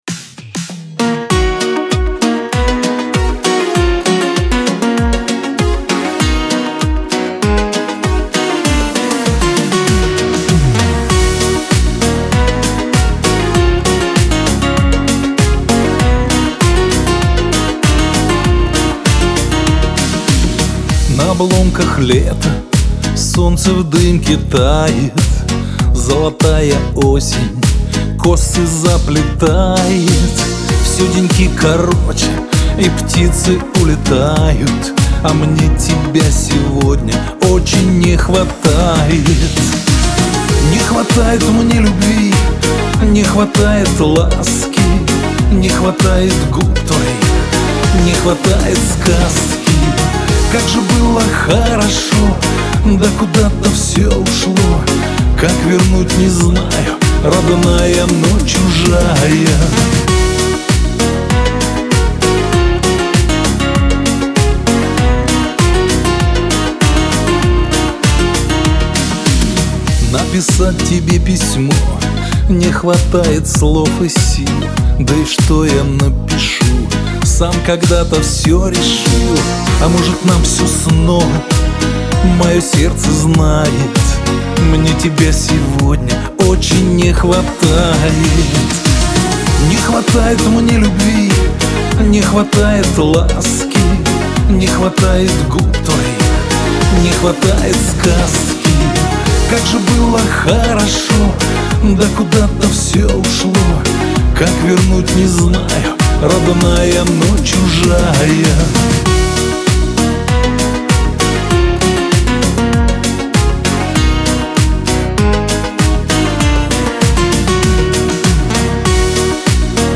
грусть
Шансон